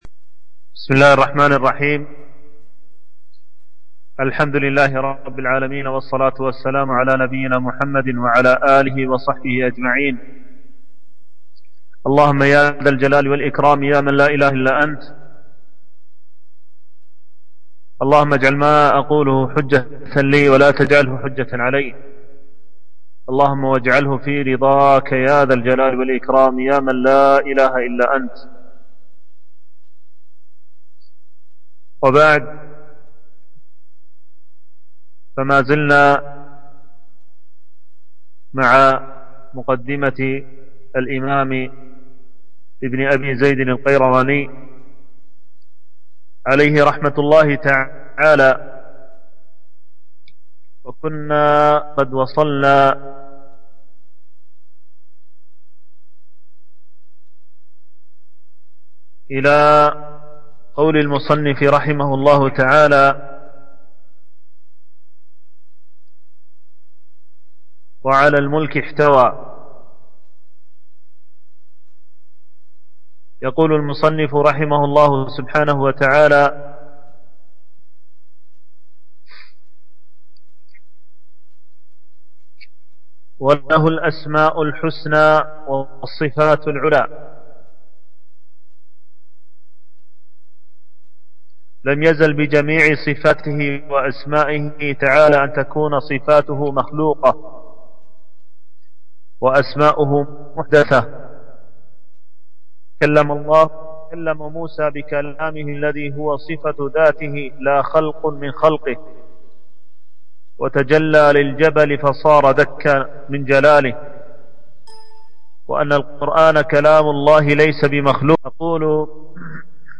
شرح مقدمة رسالة ابن أبي زيد القيرواني - الدرس العاشر